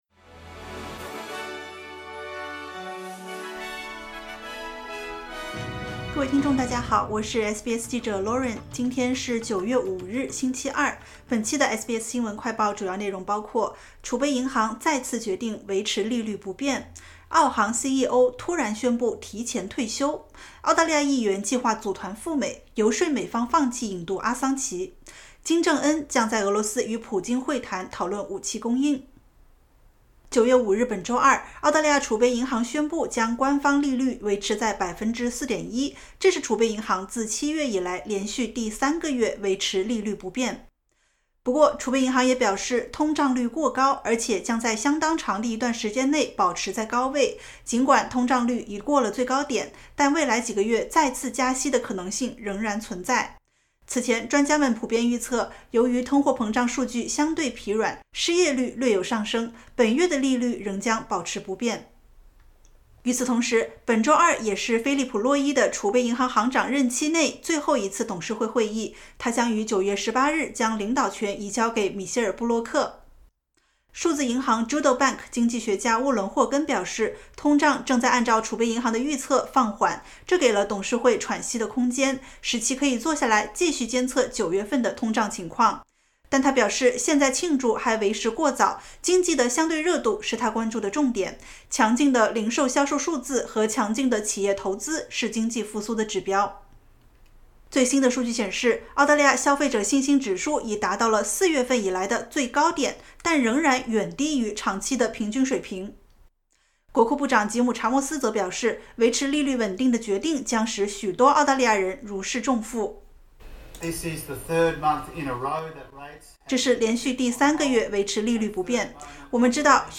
【SBS新闻快报】澳航深陷争议 首席执行官乔伊斯突然宣布提前退休